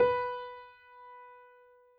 piano_059.wav